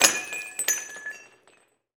Index of /90_sSampleCDs/Roland - Rhythm Section/PRC_Guns & Glass/PRC_Glass Tuned